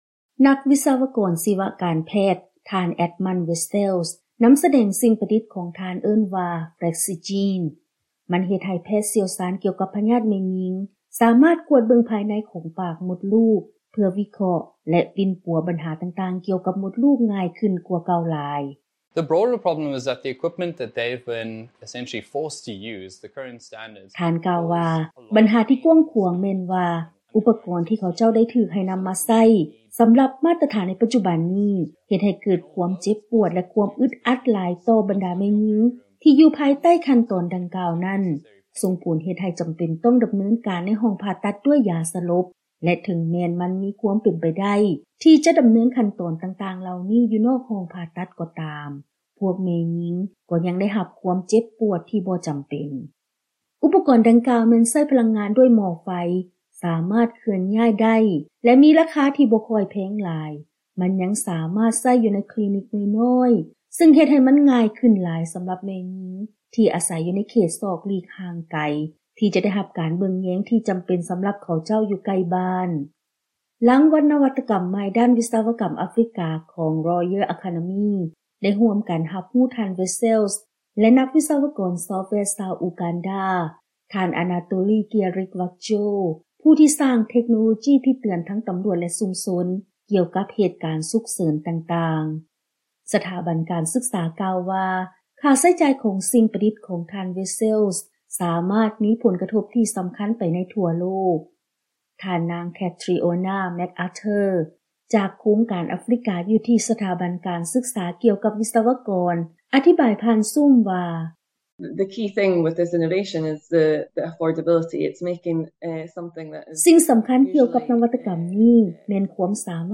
ເຊີນຟັງລາຍງານກ່ຽວກັບ ນັກວິສະວະກອນການແພດ ໄດ້ຮັບລາງວັນສູງສຸດຂອງທະວີບ